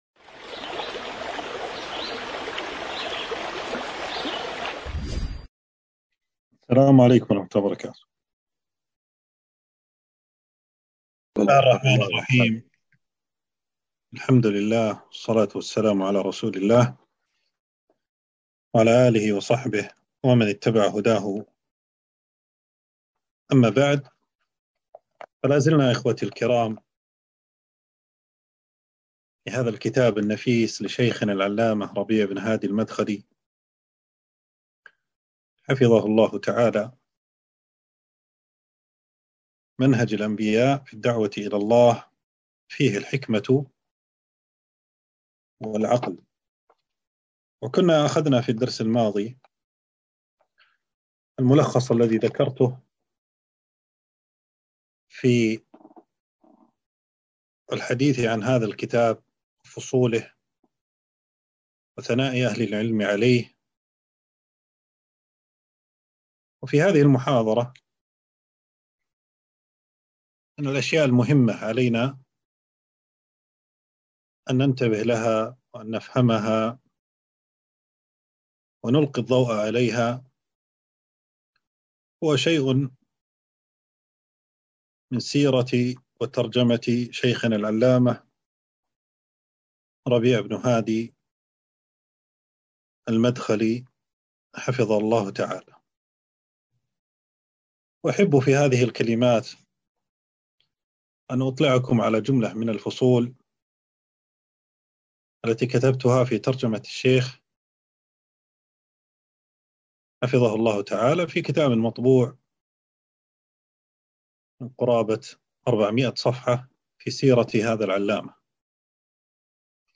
تنزيل تنزيل التفريغ محاضرة بعنوان: سيرة الشيخ العلامة أ.د. ربيع بن هادي عمير المدخلي (حفظه الله).
في مركز ابن القيم لتعليم اللغة العربية والعلوم الشرعية.